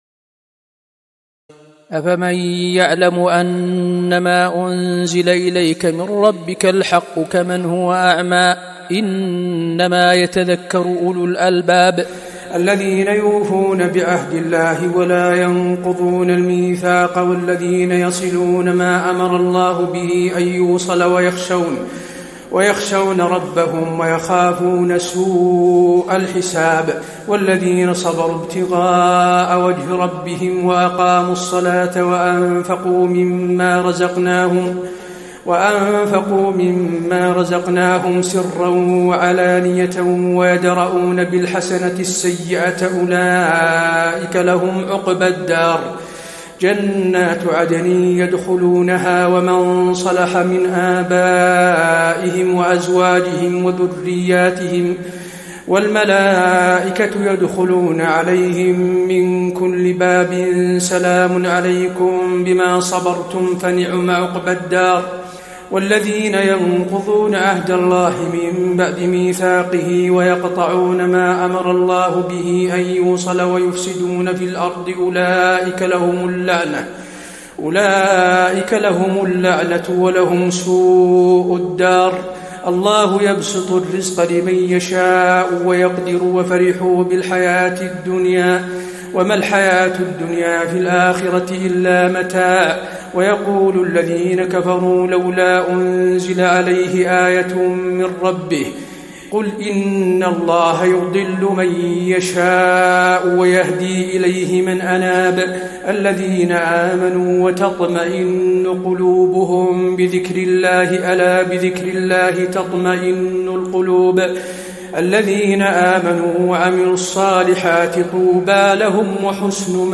تراويح الليلة الثانية عشر رمضان 1435هـ من سورتي الرعد (19-43) و إبراهيم كاملة Taraweeh 12 st night Ramadan 1435H from Surah Ar-Ra'd and Ibrahim > تراويح الحرم النبوي عام 1435 🕌 > التراويح - تلاوات الحرمين